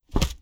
Close Combat Attack Sound 24.wav